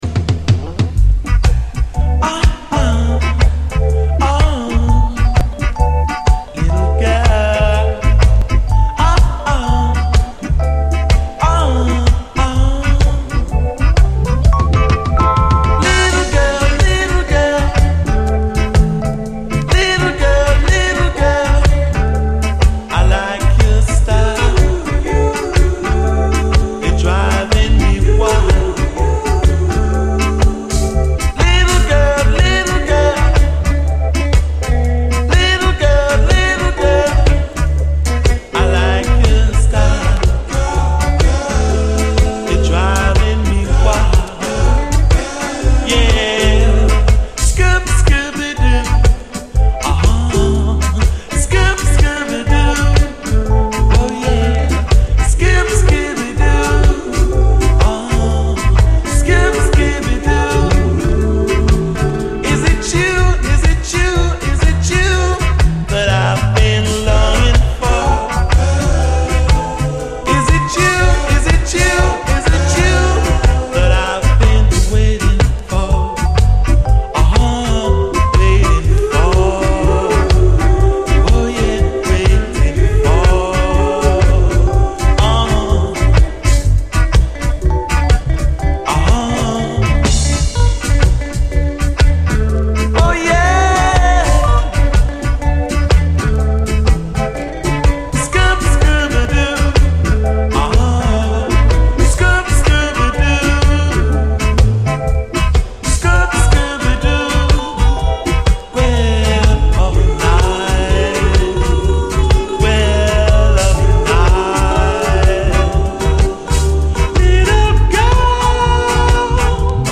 REGGAE
フワフワ・コーラスとシャッフル・ビートがゴキゲンなUKラヴァーズ！